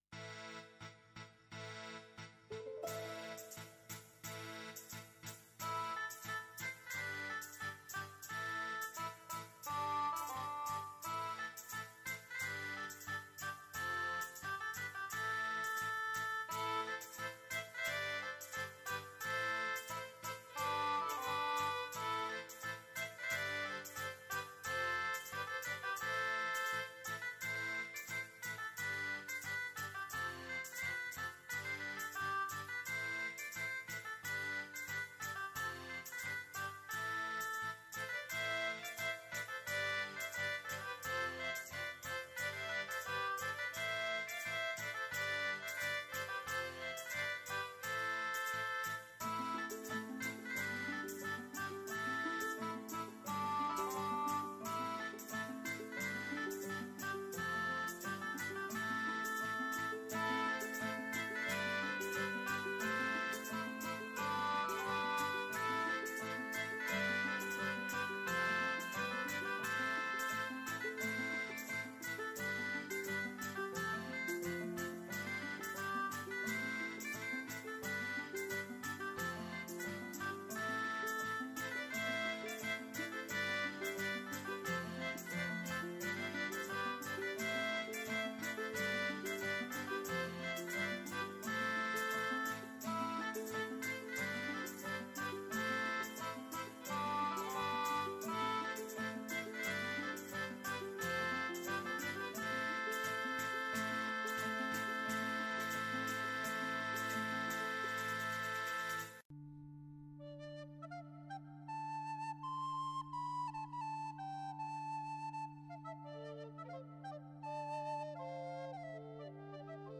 Musique médiévale